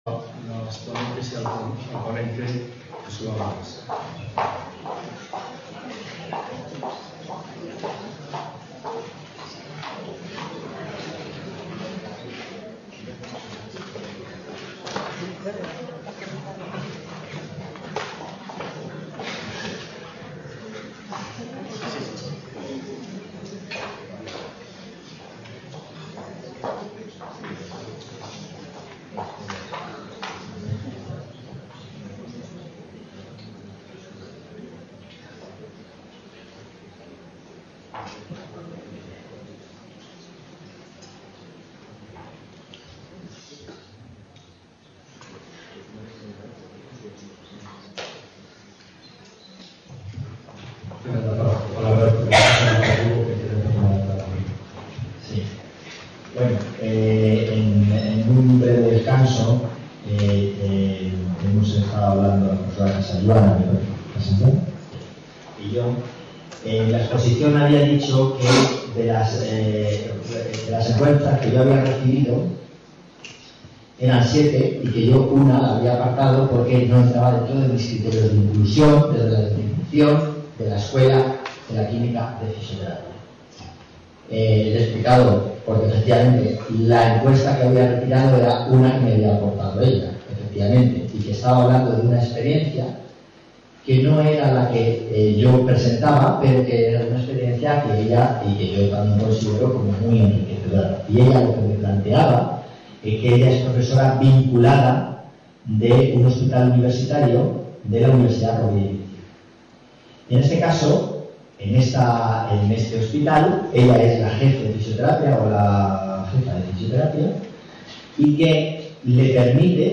Coloquio 2ªMesa | Repositorio Digital
VIII Jornadas Nacionales y IV Internacionales de Educación en Fisioterapia y IX Jornadas Interuniversitarias del Área de Conocimiento Fisioterapia
Asig: Reunion, debate, coloquio...